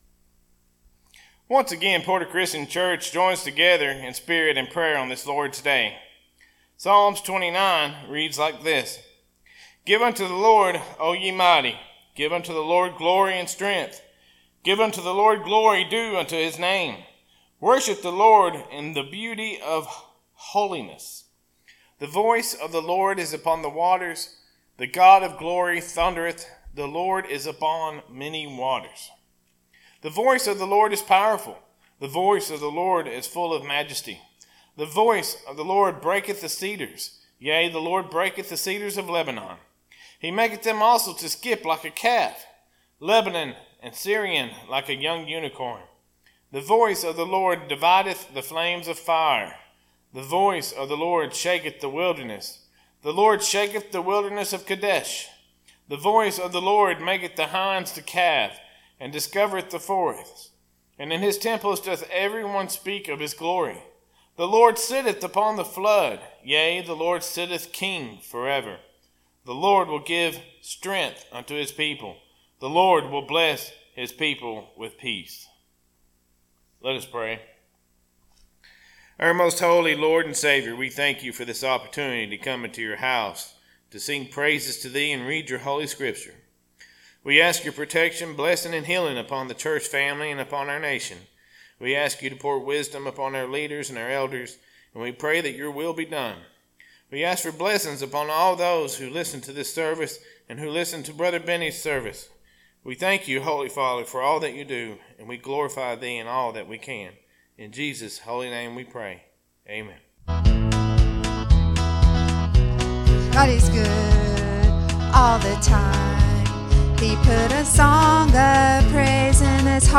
Sermons - Porter Christian Church